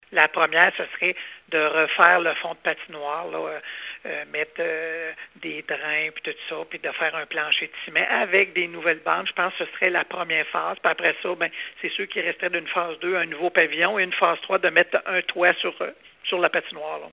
Le maire de la municipalité, Alain Fortin, précise que les deux autres phases de travaux envisagées pour le projet de réfection de la patinoire consisteraient à faire l’ajout d’un toit et d’un pavillon.